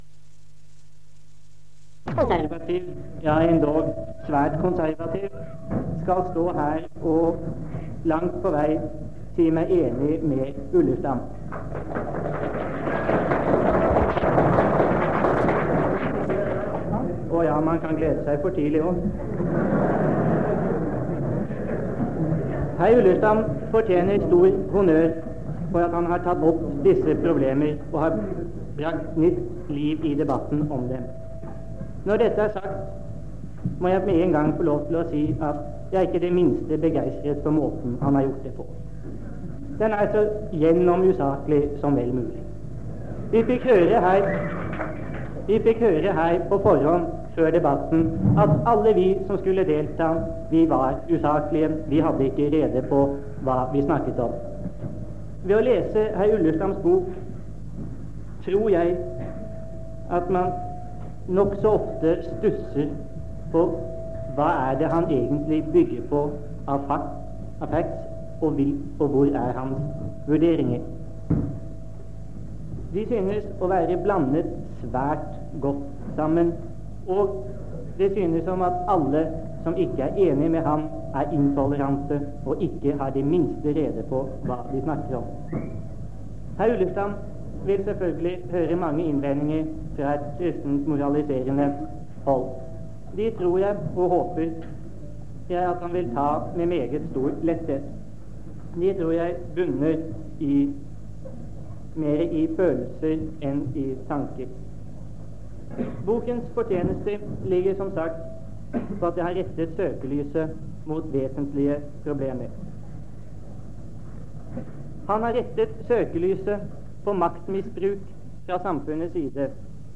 Lydbånd og foto, Foredrag, debatter og møter
01:44:11 B1: Debattinnlegg fra publikum 00:53:18 B2